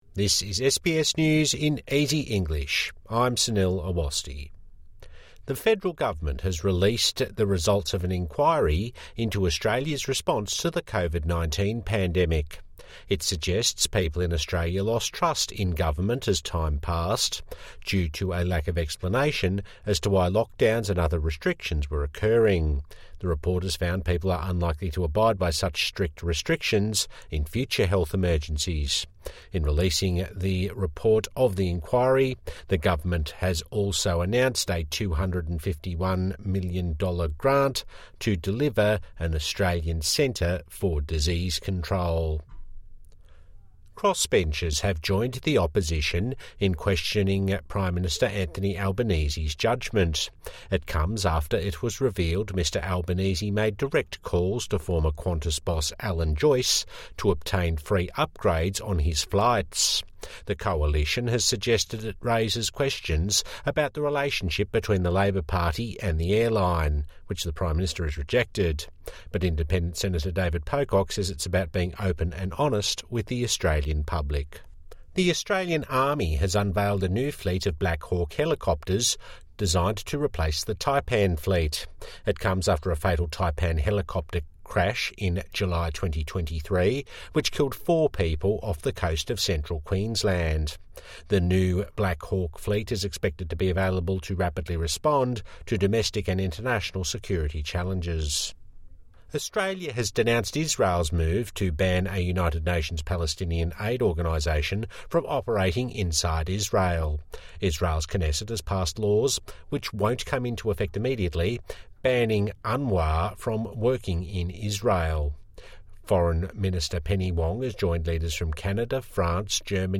A five minute daily news wrap for new English speakers and those with disability